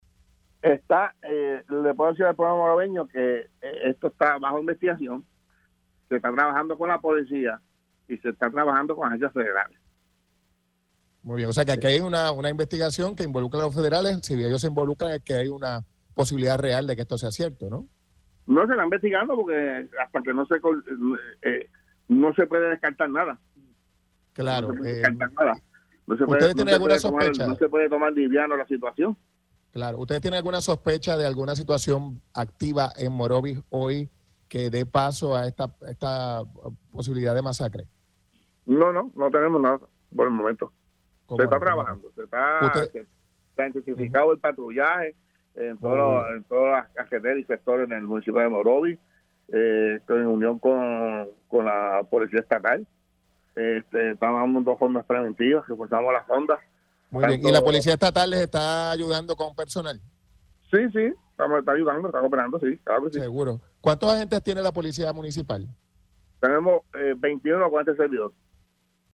202-ALVIN-RODRIGUEZ-COMISIONADO-POLICIA-MOROVIS-INVESTIGAN-AMENAZA-DE-MASACRE-JUNTO-A-LOS-FEDERALES.mp3